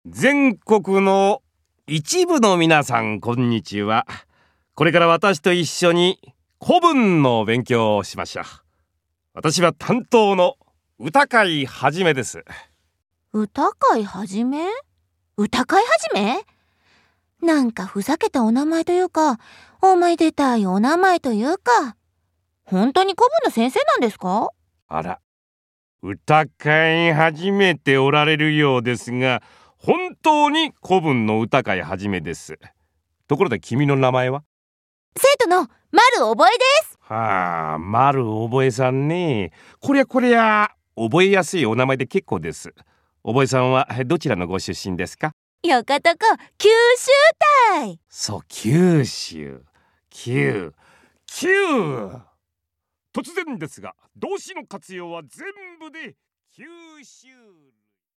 動詞活用の種類（講義）